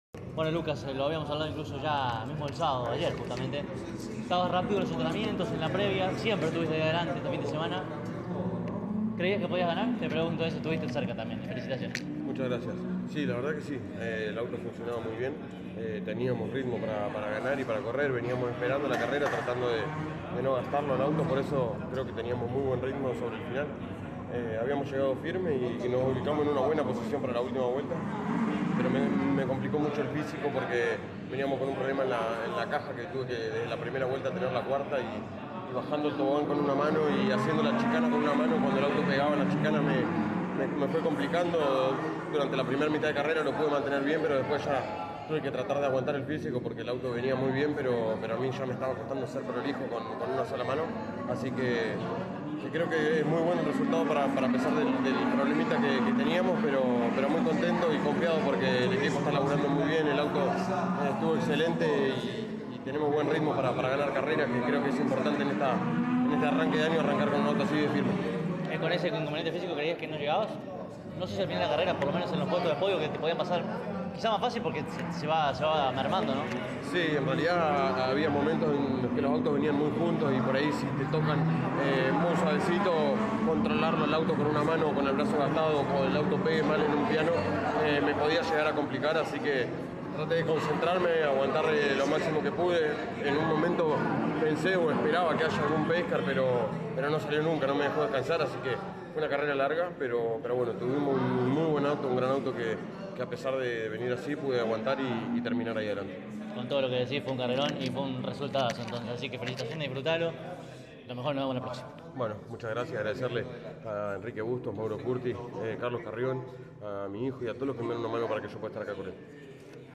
Todos ellos dialogaron con CÓRDOBA COMPETICIÓN, y aquí debajo, en orden, podés oir sus voces: